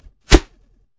DungeonCrawler/assets/sounds/auto_attack_002_48000hz.wav at 4182eea3ac0e56f8d3d98bb1840d64f6ab67a336
Audio: Finale SFX hinzugefügt, alte MusicGen-Tests entfernt